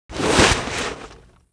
bknife_draw.wav